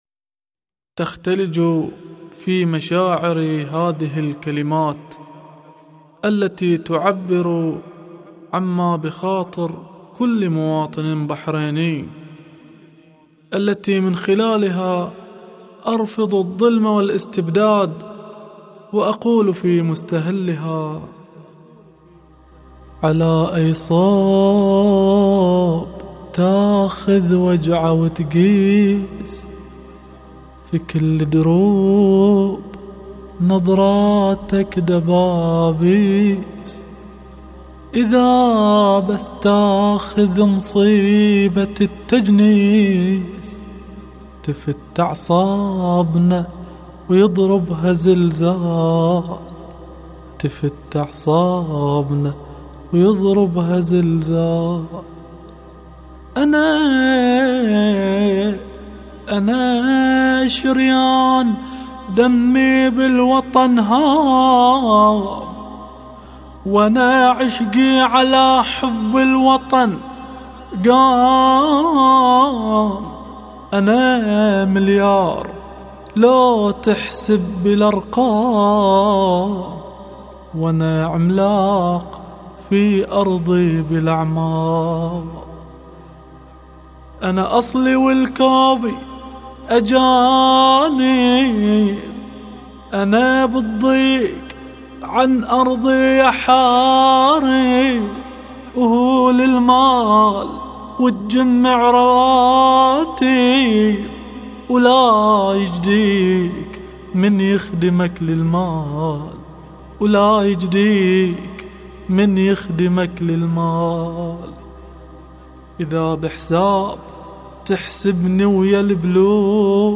نشيد
أناشيد بحرينية